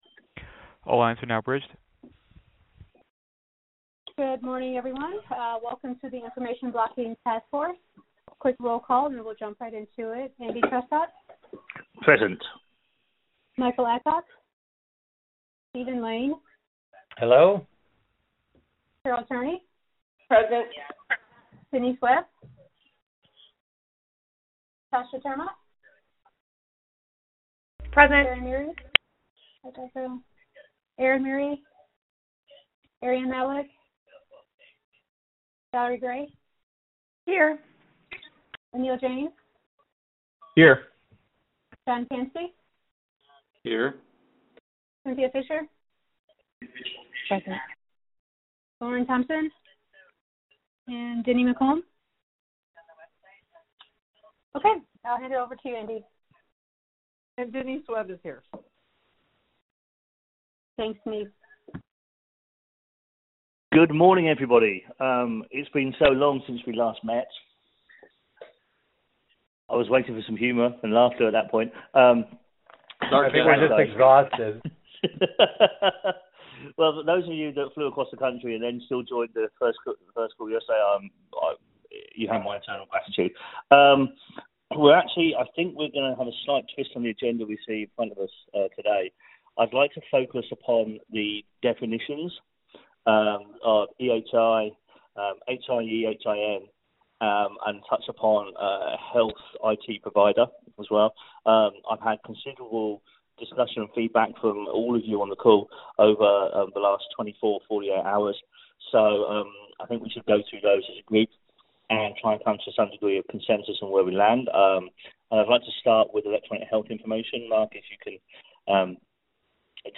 2019-04-12_IACC_VirtualMeeting_Audio